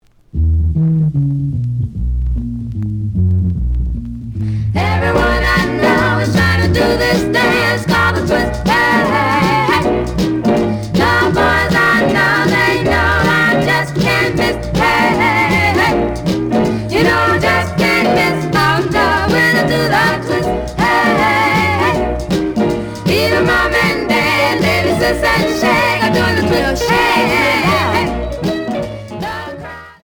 試聴は実際のレコードから録音しています。
●Genre: Rhythm And Blues / Rock 'n' Roll